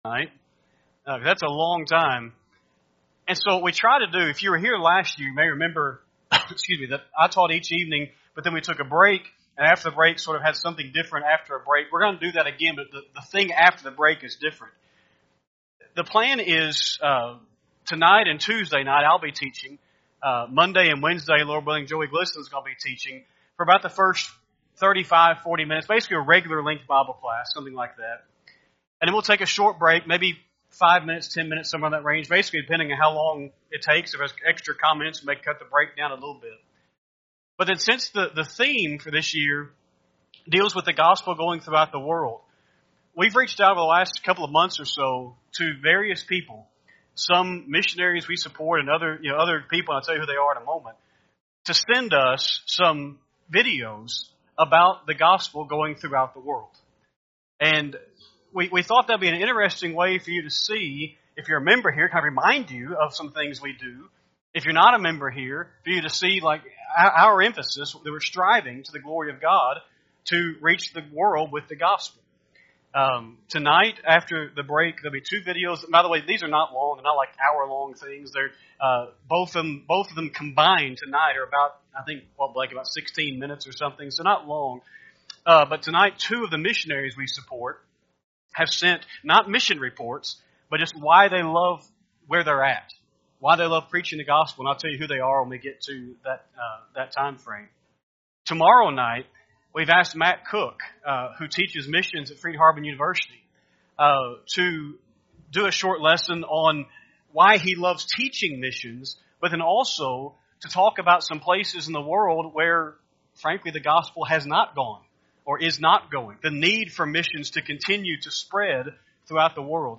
Sunday PM VBS 6/2/24